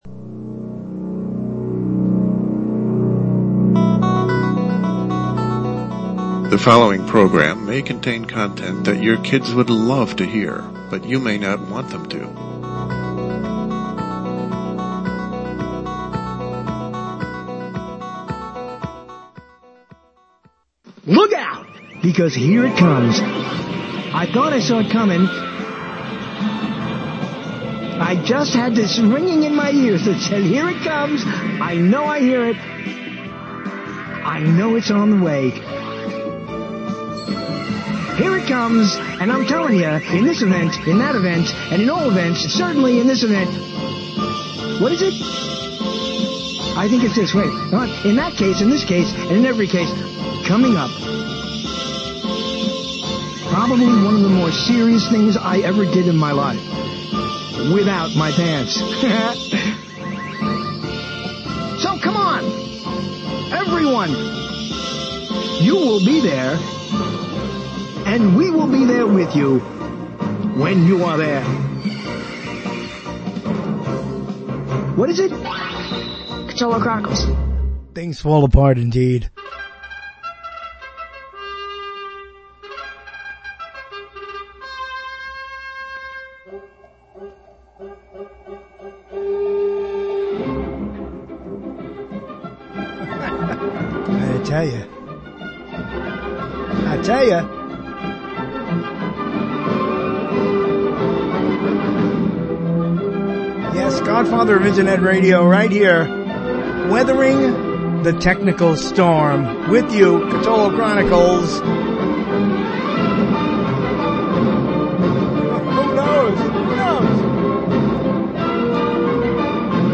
LIVE, Thursday, June 8 at 9 p.m. EST